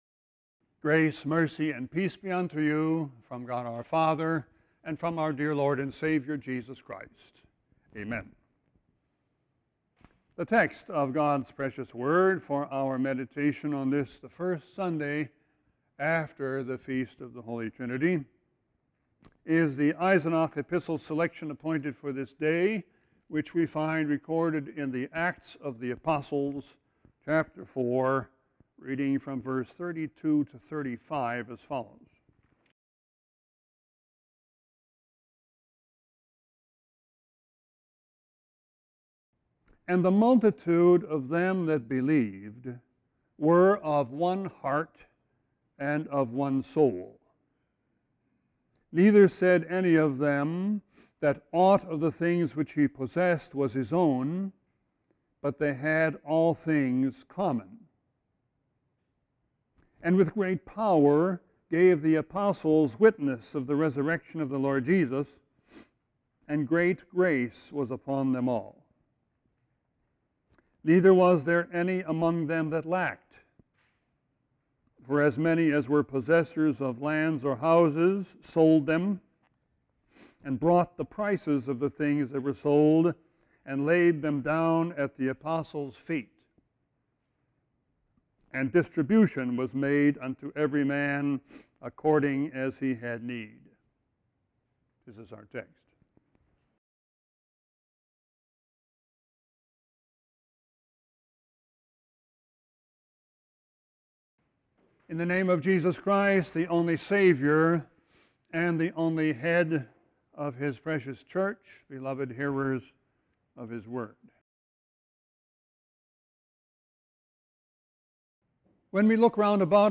Sermon 5-29-16.mp3